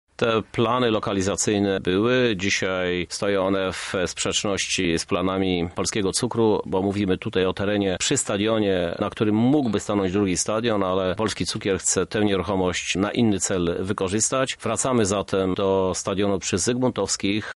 Nowy obiekt miał powstać w sąsiedztwie Areny Lublin lecz jest to nie możliwe – mówi Prezydent Lublina, Krzysztof Żuk: